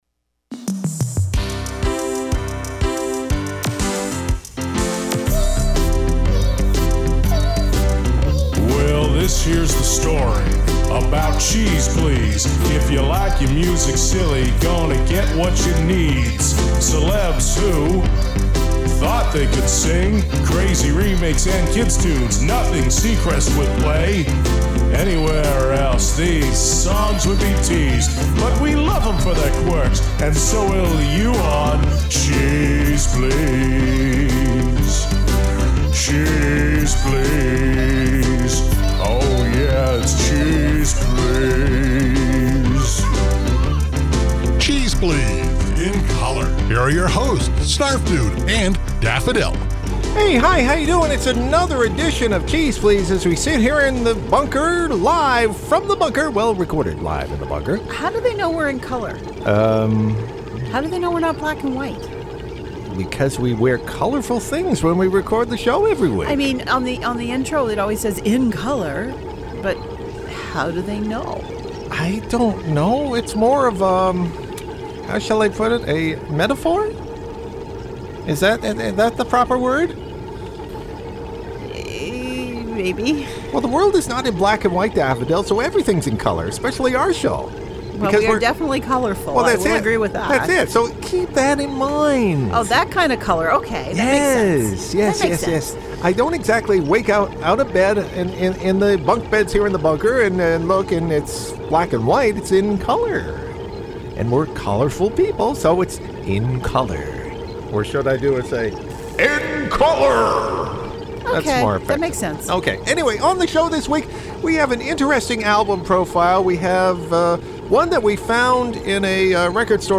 An odd comedy album of 60s military humor, flies past our radar...